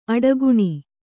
♪ aḍaguṇi